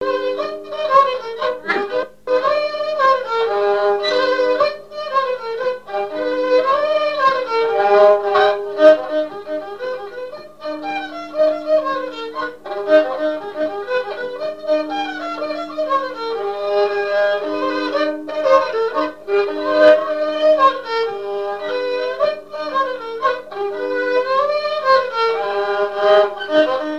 Mémoires et Patrimoines vivants - RaddO est une base de données d'archives iconographiques et sonores.
danse : polka piquée
Pièce musicale inédite